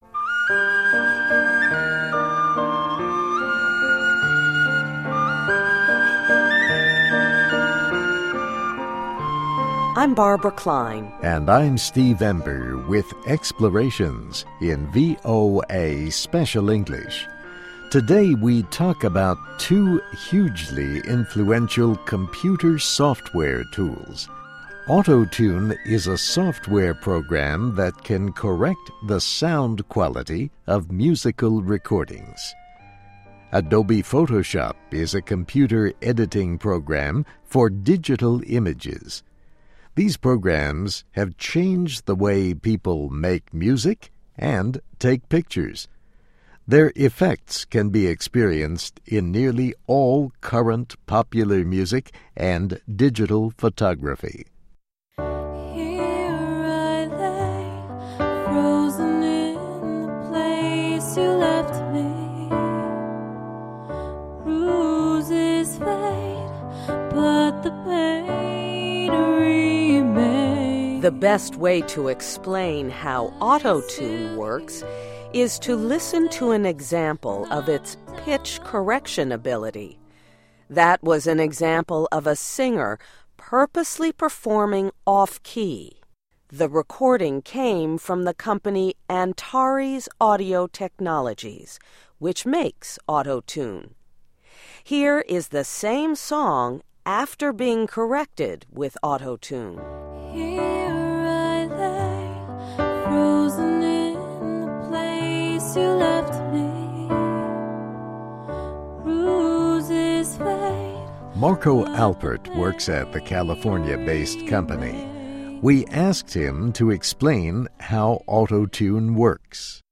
That was an example of a singer purposely performing off key.
Here is the same song after being corrected with Auto-Tune.